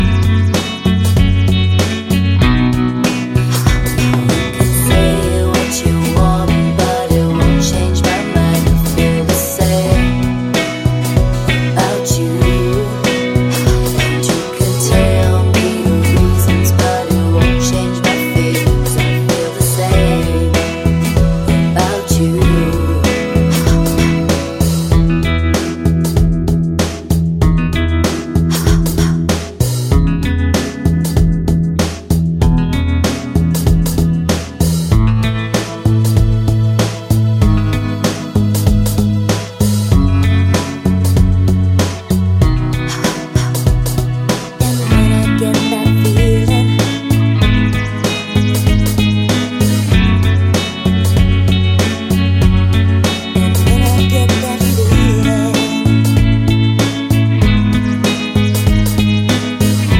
Medley Pop (1990s)